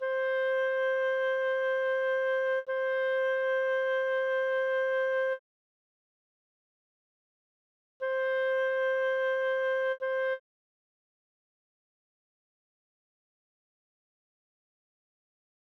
Toward the goal of explaining and demonstrating what reverberation does, I created three WAVE files of the same notes in your example, where the instrument is the Notion 3 bundled Clarinet . . .
(1) This is the Notion 3 bundled Clarinet with no added reverberation, so it is the "dry" Clarinet:
Notion 3 Bundled Clarinet -- DRY -- WAVE file (2.8MB, approximately 16 seconds)
N3-Clarinet-Dry.wav